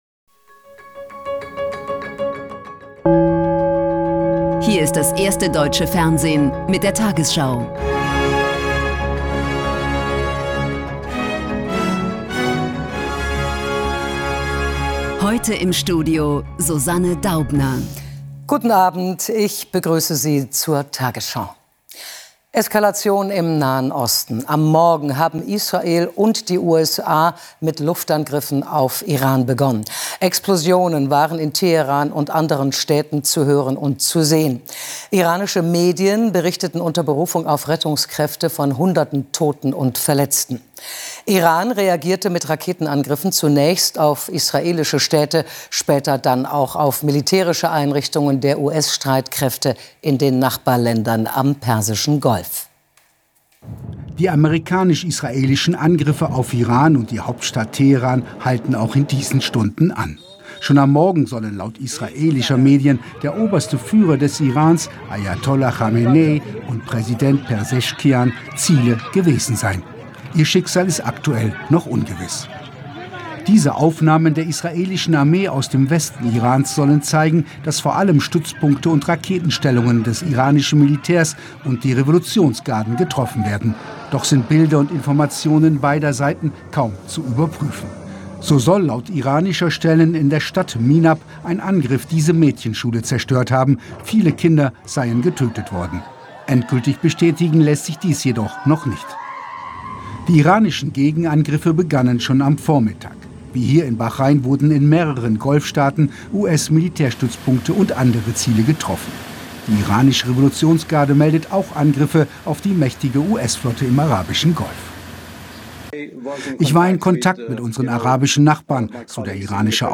tagesschau 20:00 Uhr, 28.02.2026 ~ tagesschau: Die 20 Uhr Nachrichten (Audio) Podcast